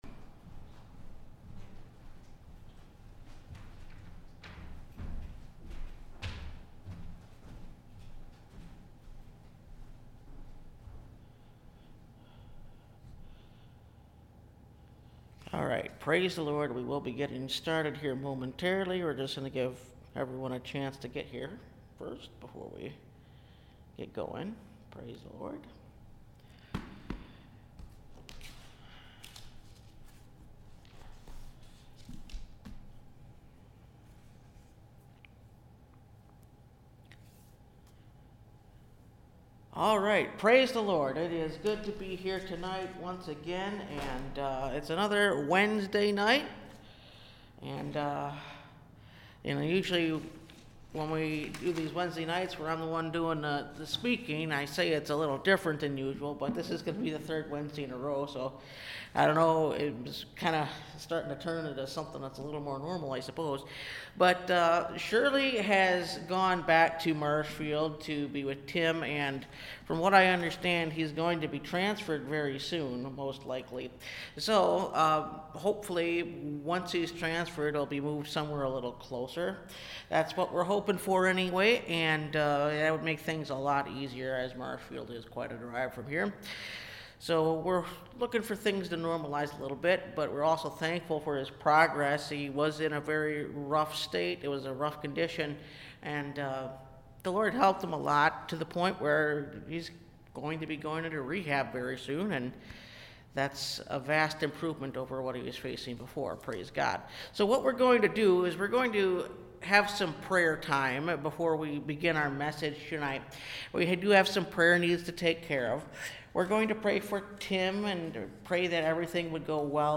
A Marvelous Thing – Last Trumpet Ministries – Truth Tabernacle – Sermon Library